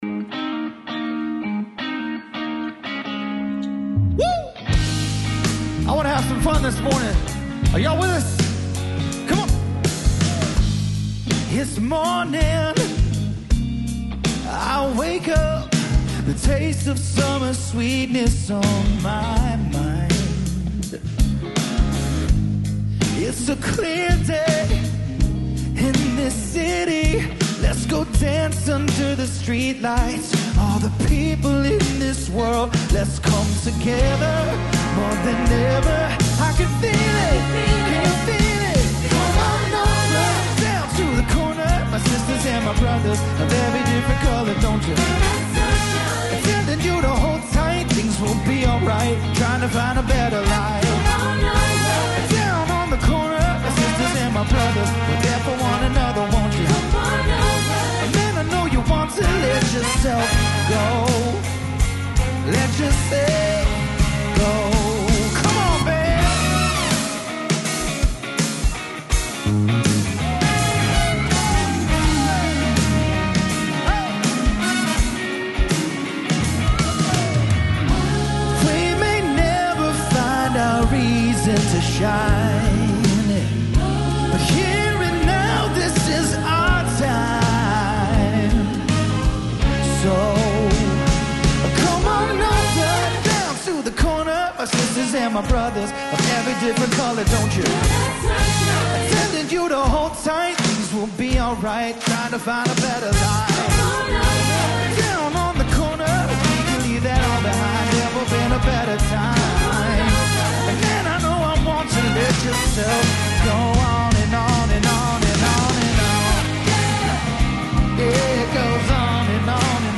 About This Message Andy Stanley - Mar 26, 2023 Message Only Music and Message The church is God's agent of transformation for the world, and the church is your opportunity to take part in that transformation.